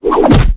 bash2.ogg